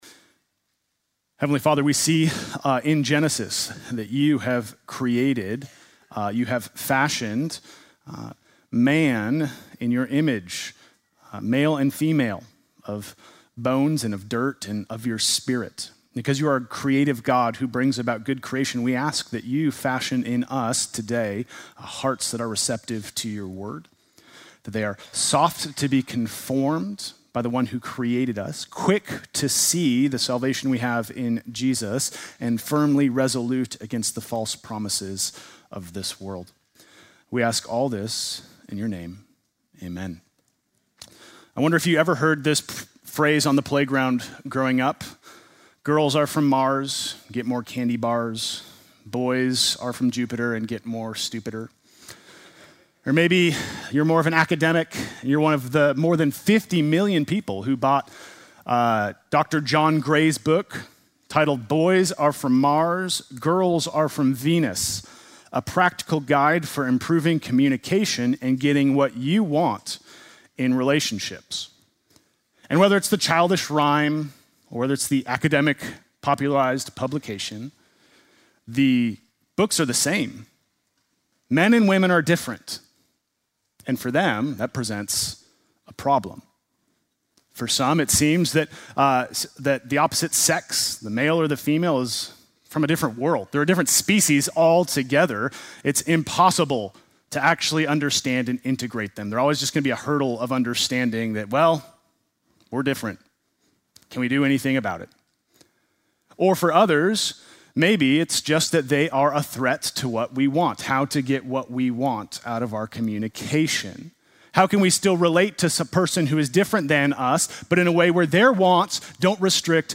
Sunday morning message August 24